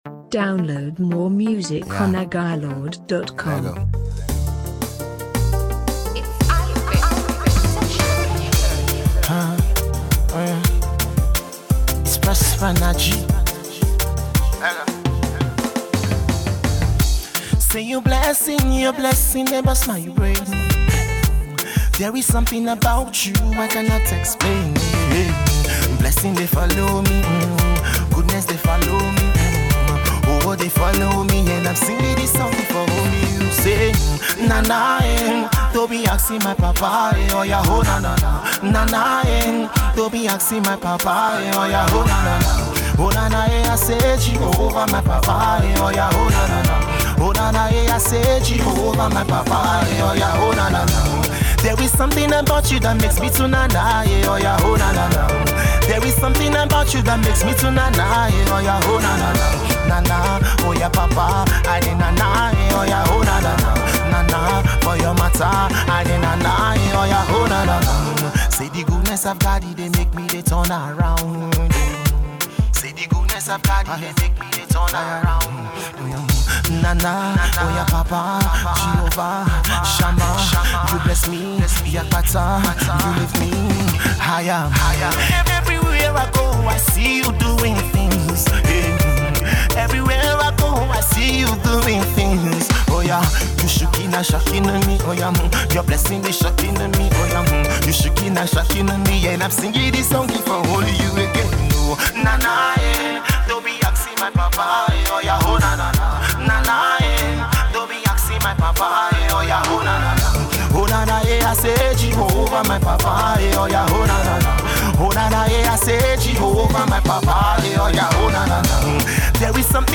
gospel
with the cool vibes that speaks of happiness.
this song is bound to keep u dancing and praising always.